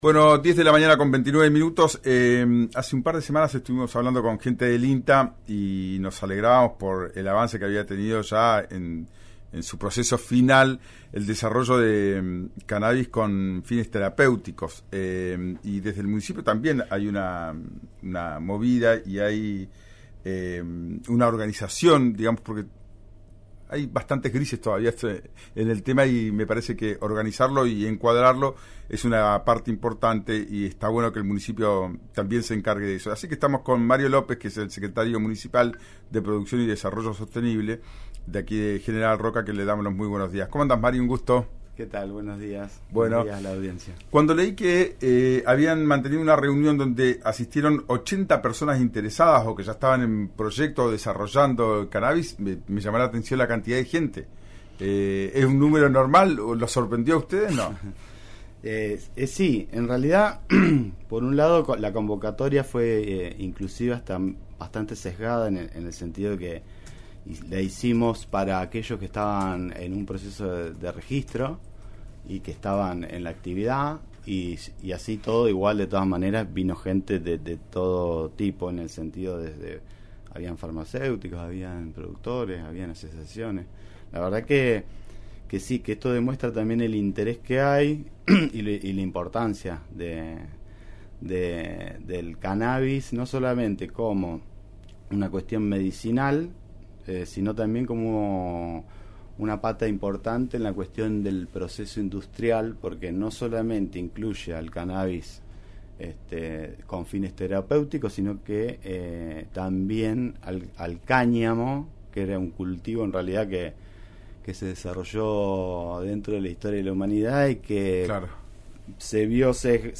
Un cultivo que se desarrolló dentro de la historia de la humanidad pero que se vió impactado por la prohibición, cuando en realidad es un cannabis que no tiene contenido psicoactivo», comentó el secretario municipal de Producción y Desarrollo Sostenible, Mario López, en dialogo con el programa «Ya es tiempo» de RÍO NEGRO RADIO.